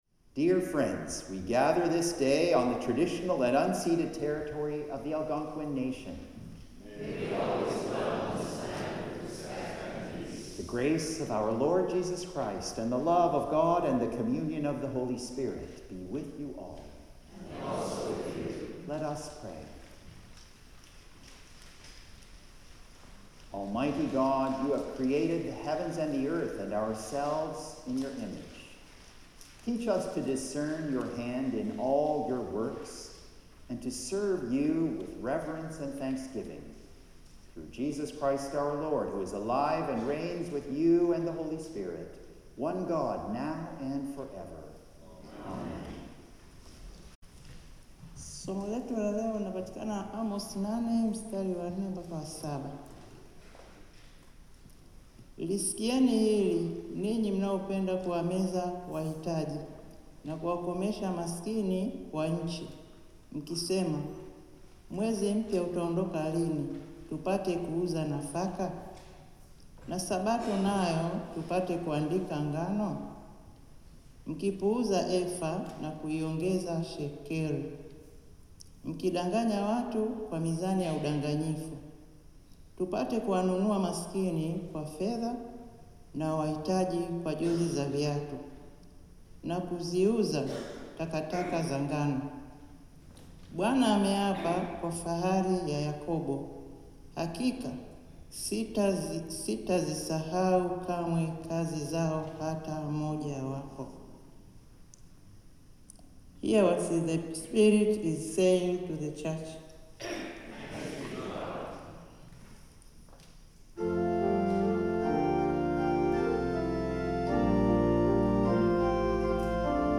Sermons | St John the Evangelist
Hymn 398: Let Us With A Gladsome Mind
The Lord’s Prayer (sung)